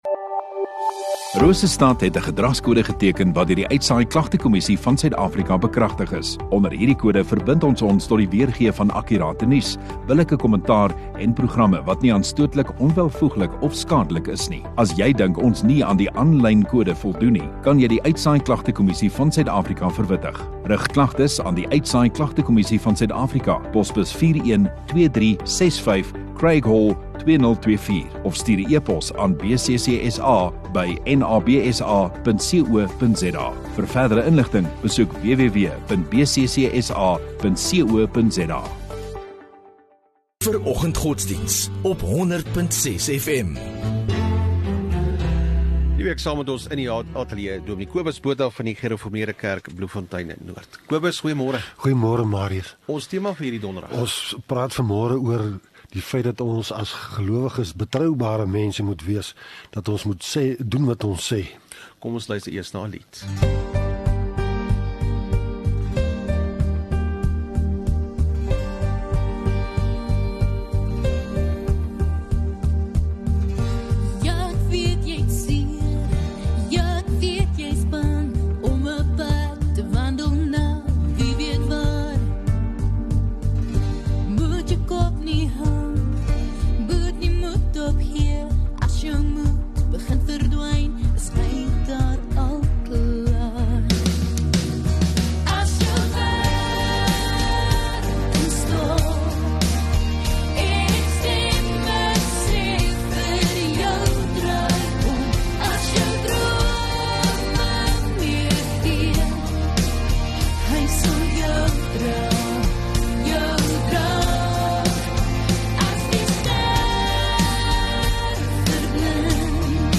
3 Oct Doensdag oggenddiens